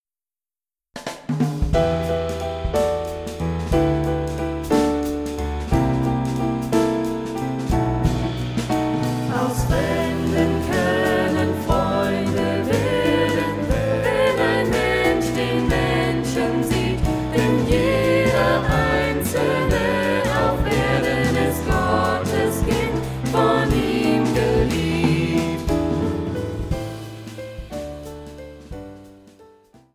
Gesang und Schlagzeug
Gesang und Klavier
Hammond Organ